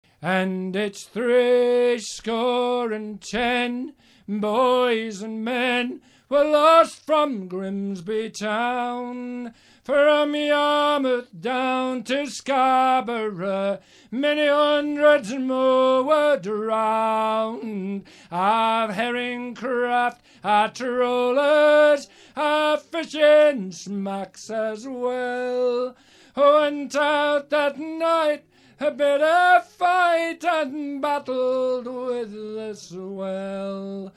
Traditional Anglo American and a few modern pieces, usually in a traditional style, and sea songs and shanties.